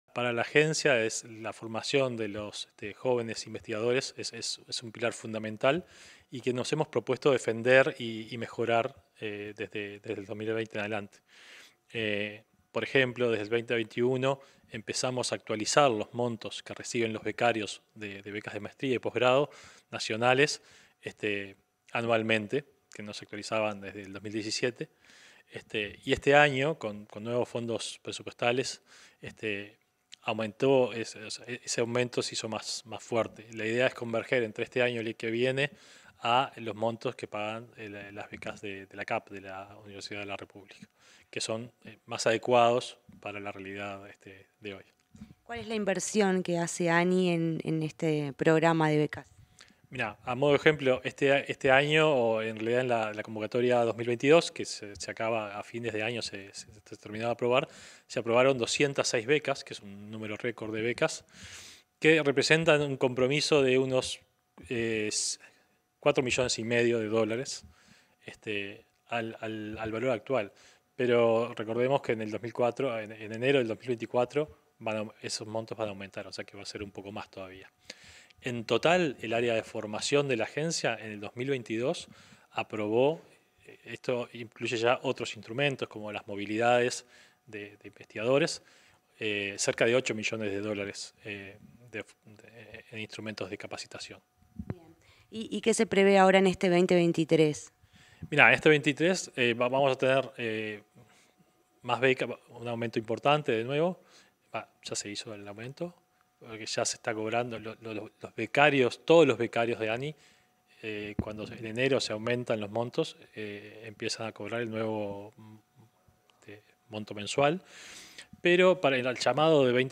Entrevista al presidente de ANII, Flavio Caiafa
Comunicación Presidencial dialogó con el presidente de la Agencia Nacional de Investigación e Innovación (ANII), Flavio Caiafa, sobre la consolidación de los esfuerzos para jóvenes investigadores. El objetivo del programa de becas de posgrados nacionales de la ANII es fortalecer las capacidades en recursos humanos con las que cuenta el país, a través del financiamiento de becas de maestrías y doctorados.